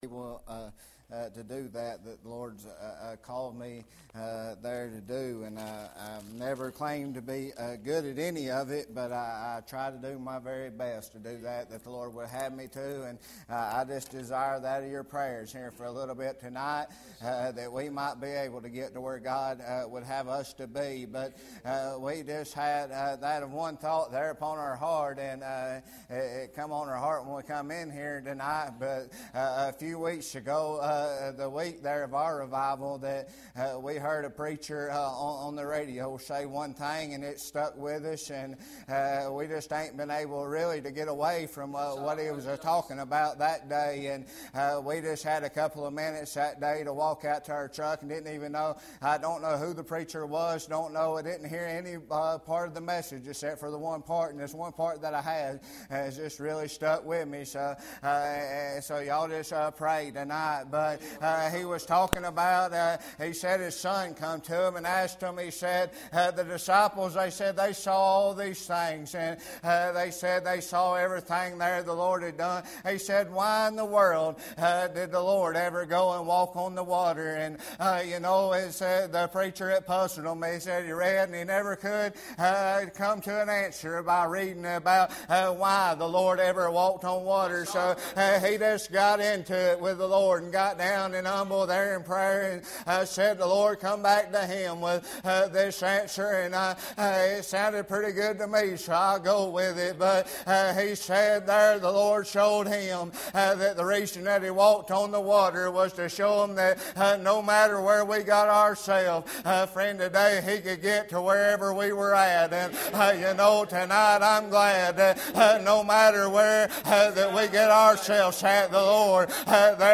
Sermon
Sermon media